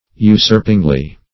usurpingly - definition of usurpingly - synonyms, pronunciation, spelling from Free Dictionary Search Result for " usurpingly" : The Collaborative International Dictionary of English v.0.48: Usurpingly \U*surp"ing*ly\, adv.